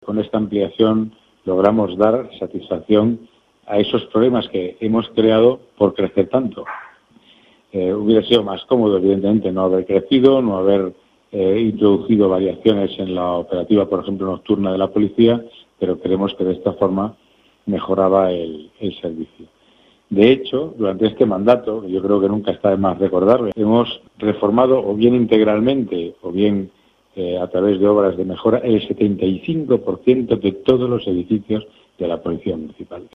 Nueva ventana:El concejal de Seguridad y Servicios a la Comunidad, Pedro Calvo, habla de la mejora de los edificios de la policia municipal